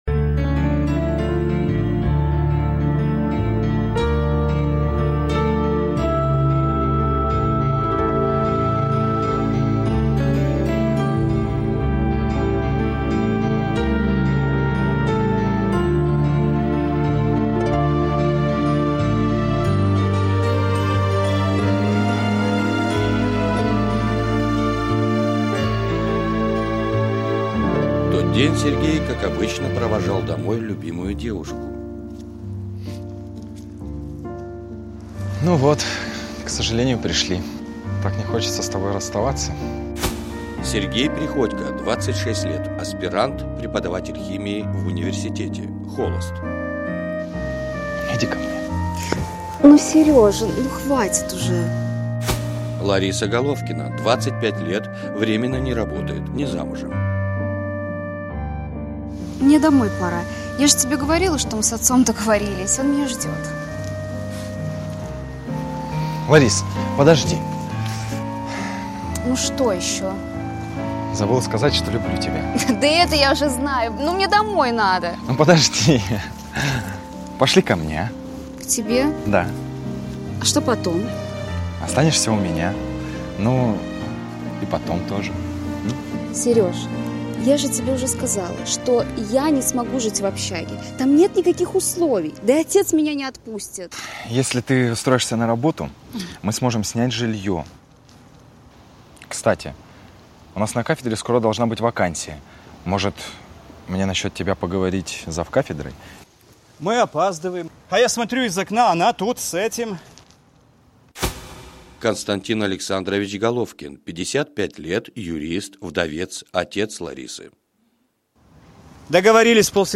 Аудиокнига Мой герой | Библиотека аудиокниг
Прослушать и бесплатно скачать фрагмент аудиокниги